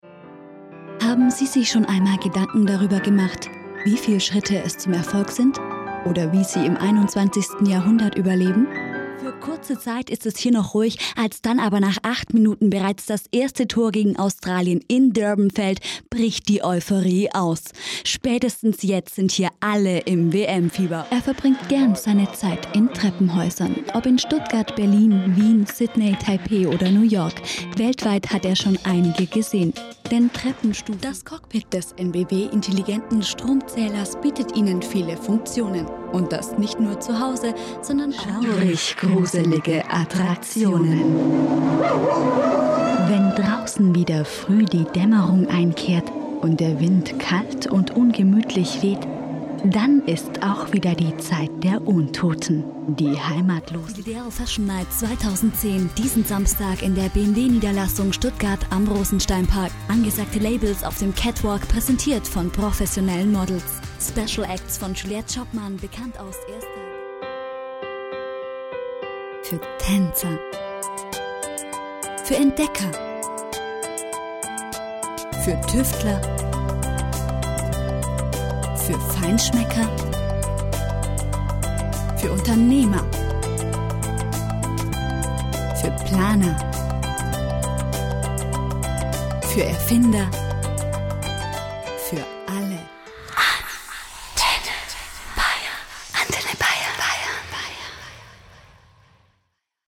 - vielseitige Stimme! - am Mikrophon seit 2008! - als Moderatorin & Reporterin (Radio & TV), Station Voice und Werbestimme - flexibel und zuverlässig
Kein Dialekt
Sprechprobe: eLearning (Muttersprache):
Sprechprobe: Werbung (Muttersprache):